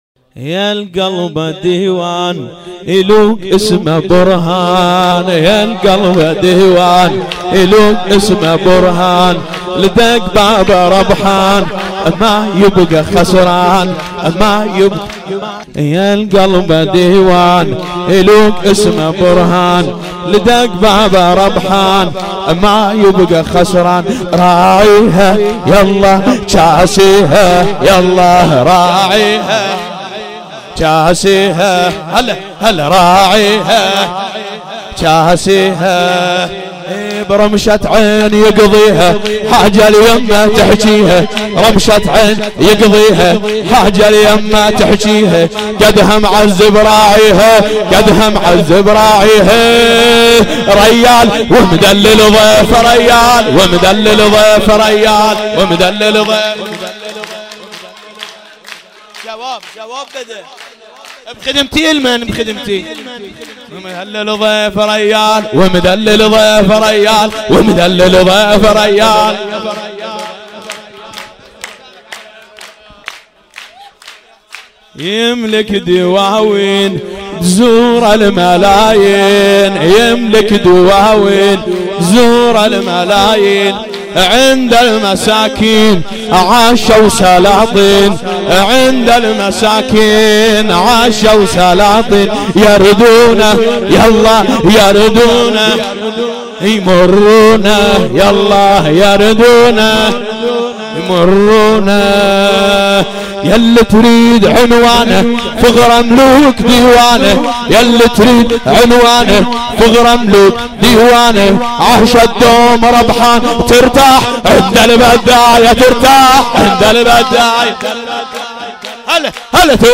سرود عربی